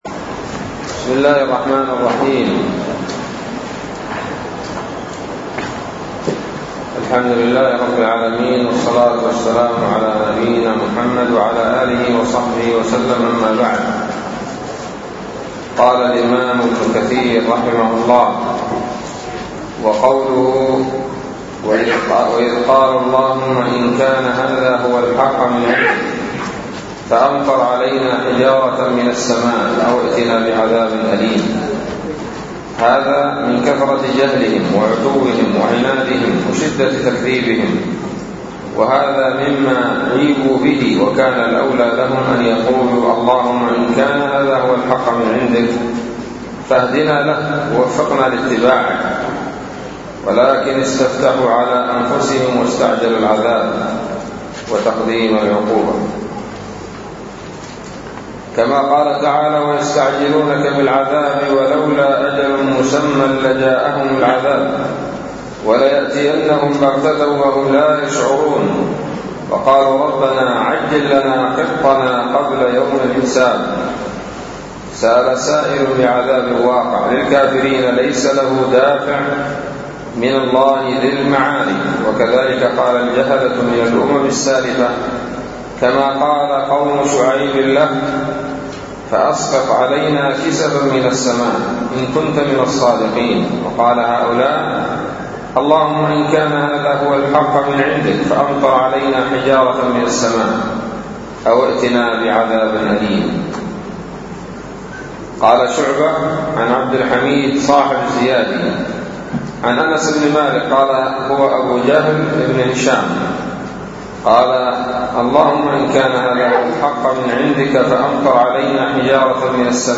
الدرس الثامن عشر من سورة الأنفال من تفسير ابن كثير رحمه الله تعالى